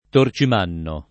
torcimanno [ tor © im # nno ]